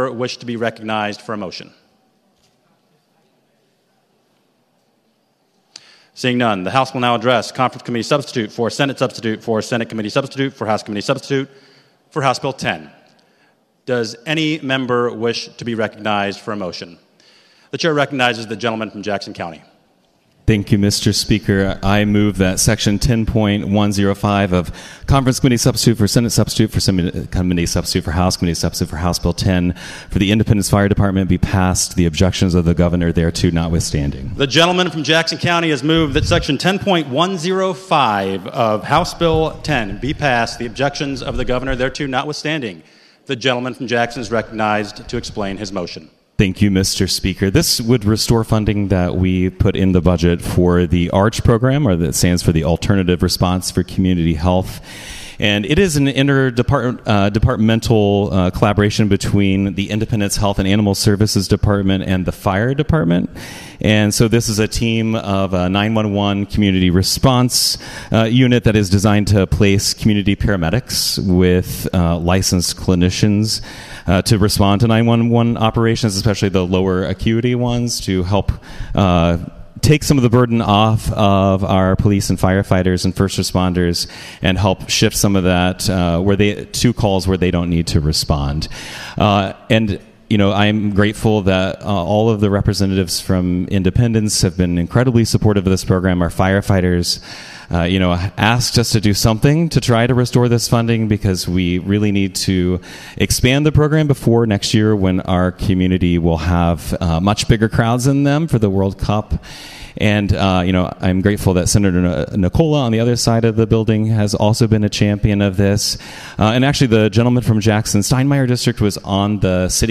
Committee Hearing Test